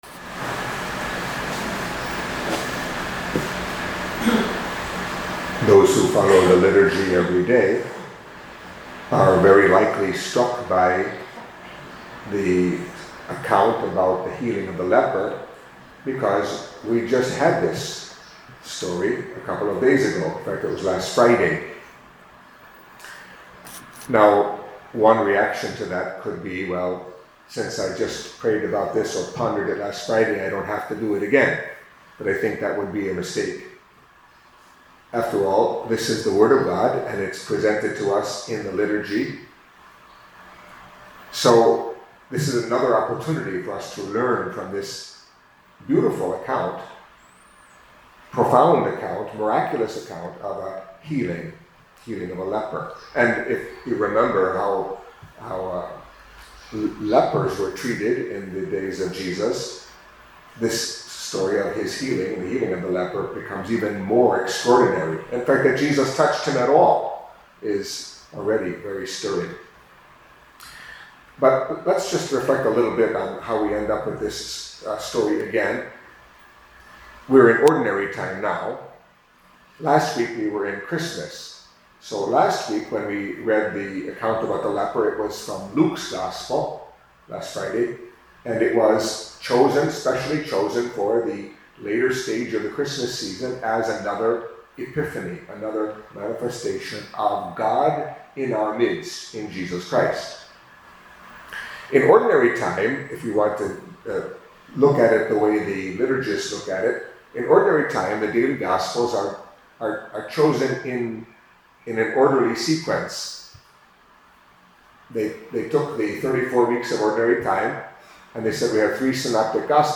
Catholic Mass homily for Thursday of the First Week in Ordinary Time